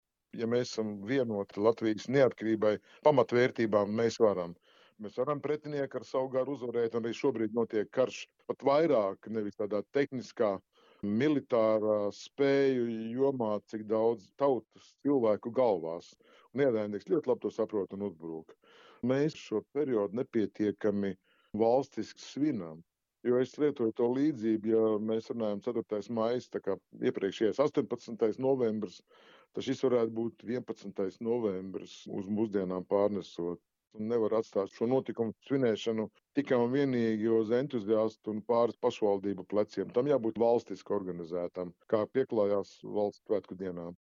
Ģenerālis, bijušais Latvijas Nacionālo bruņoto spēku komandieris Raimonds Graube